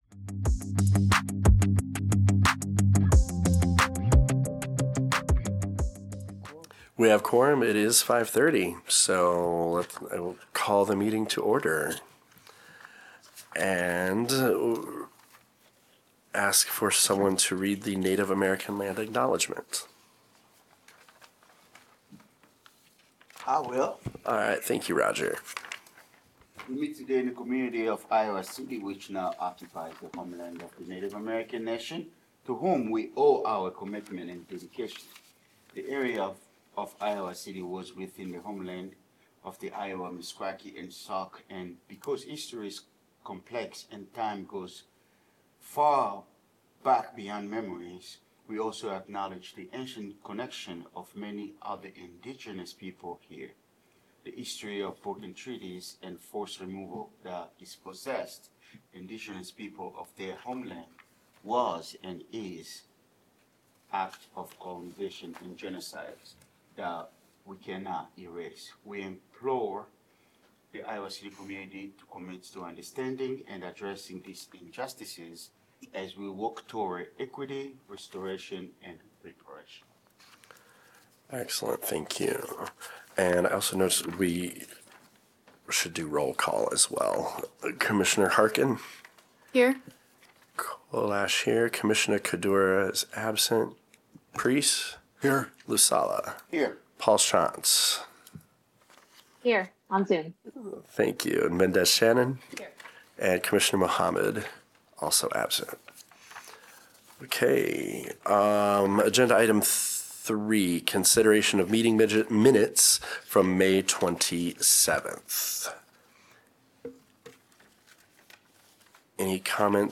Regular monthly meeting of the Human Rights Commission.